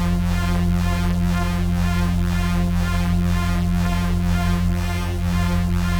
Index of /musicradar/dystopian-drone-samples/Tempo Loops/120bpm
DD_TempoDroneD_120-F.wav